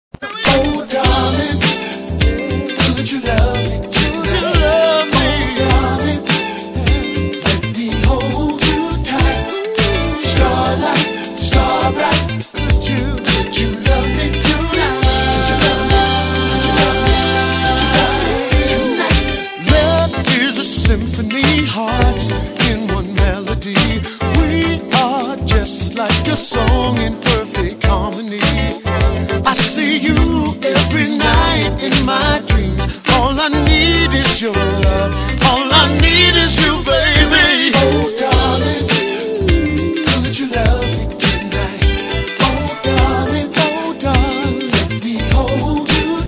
Soul/R&B/Jazz